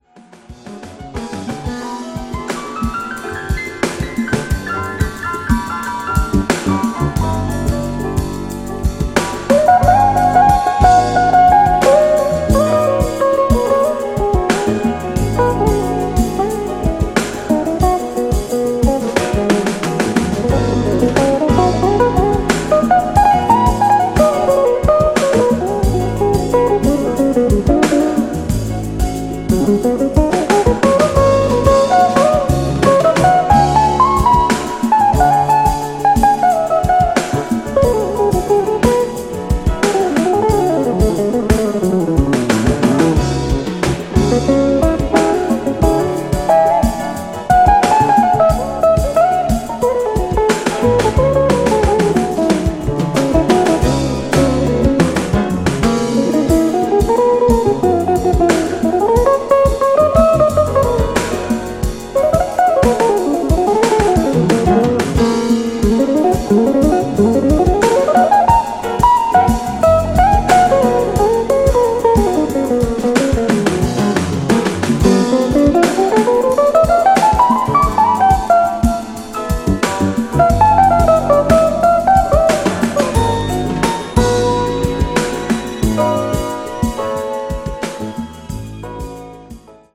【12"INCH】(レコード)
ジャンル(スタイル) JAZZ / FUNK / BALEARICA